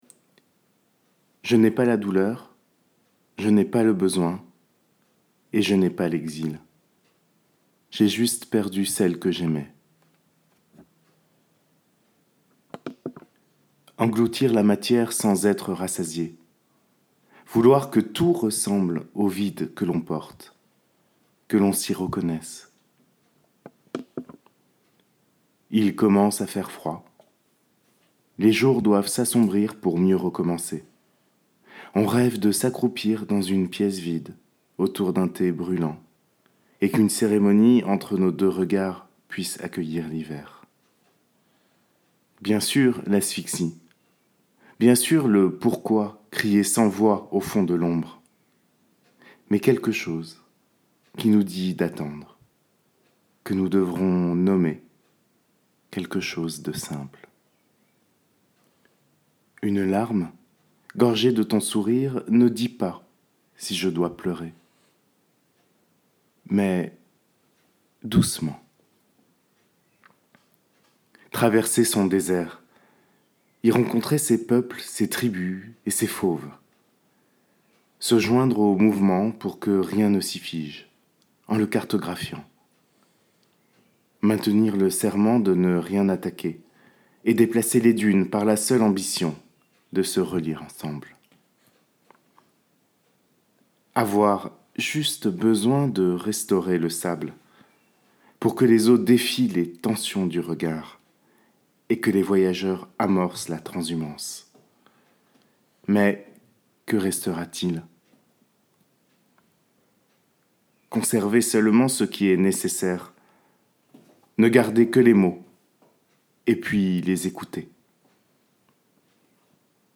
dans Poésie sonore
Ces textes étaient écoutables au casque, confortablement installé dans d’amples hamacs.